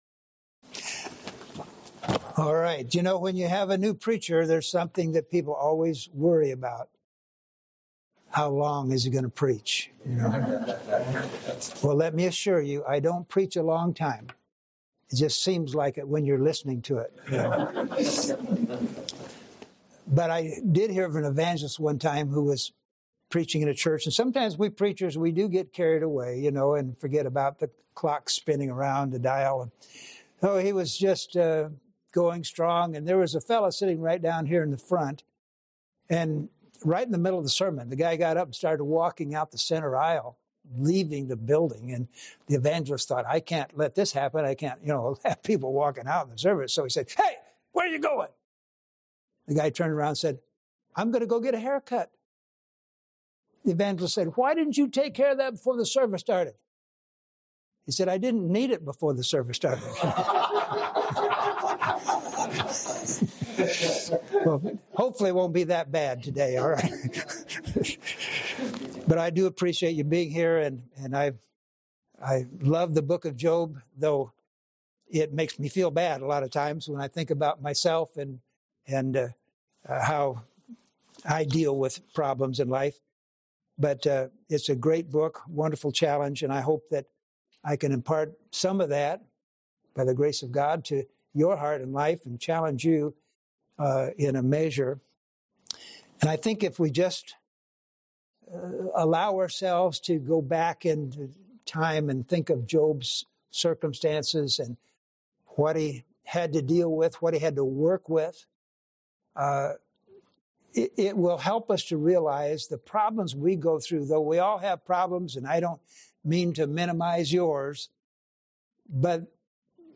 Sermons
Show Details → Sermon Information Title Opportunities in Affliction Description Message #2 of the 2025 Bible Conference. Although we usually wish to be delivered from difficulties, Job chapter 10 teaches us that times of adversity provide opportunities to relate to God in ways that would not otherwise be possible.